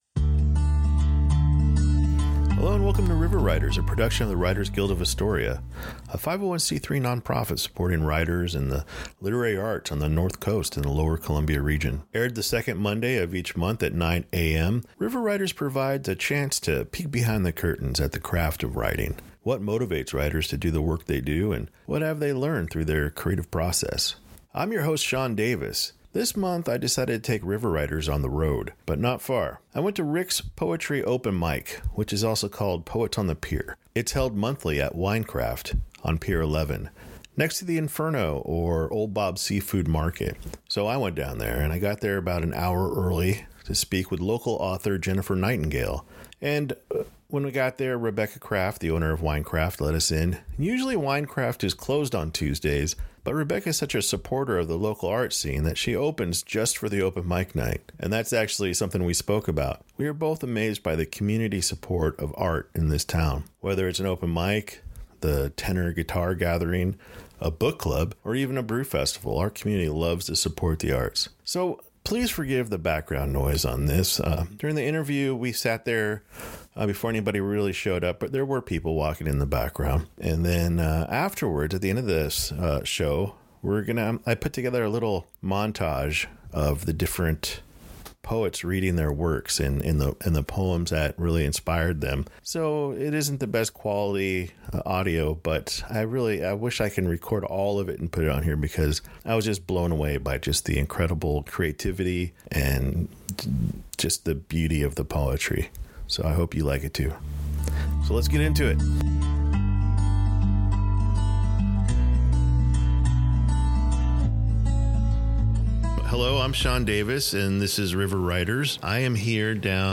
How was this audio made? River Writers is on scene to speak to some really great people and highlight this open mic at WineKraft on Pier 11 the first Tuesday of every month.